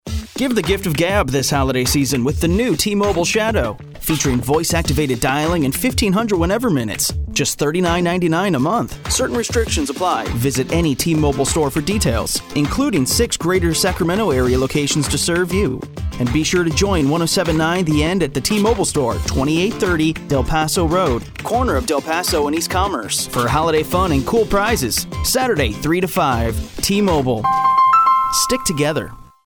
Listen Now: SAC GO air check